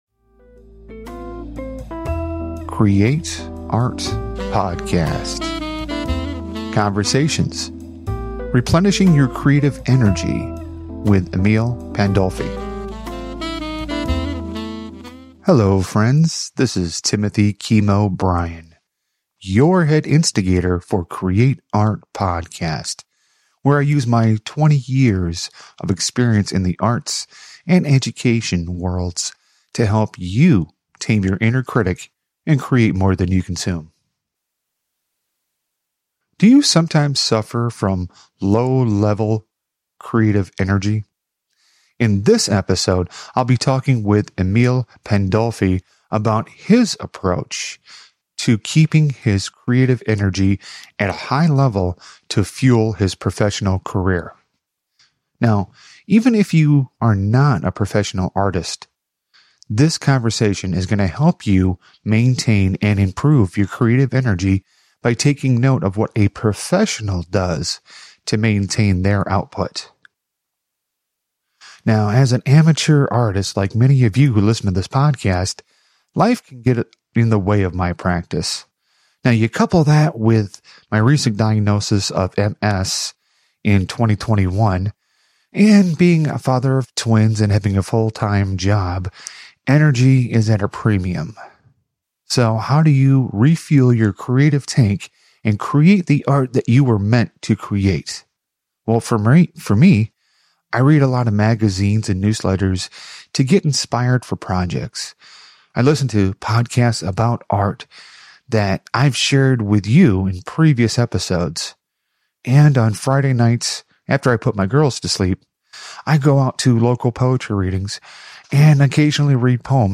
This conversation is going to help you maintain and improve your creative energy by taking note of what a professional does to maintain their output.